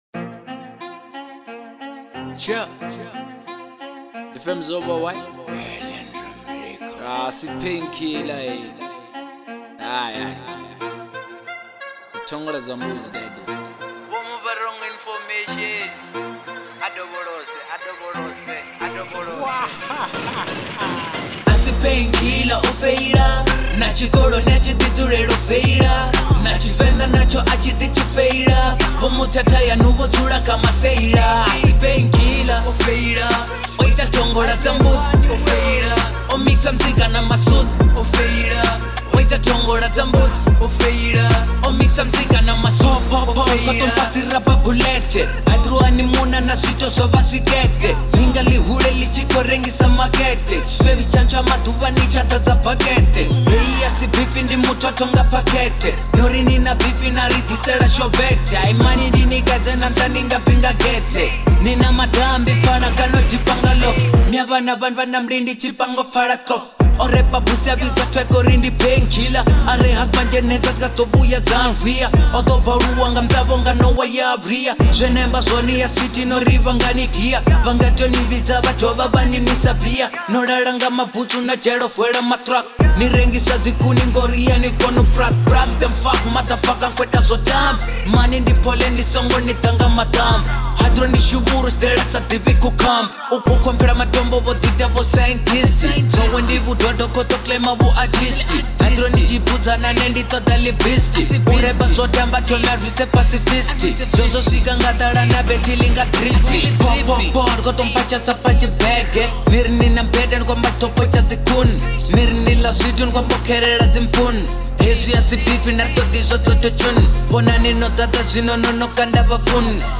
03:33 Genre : Venrap Size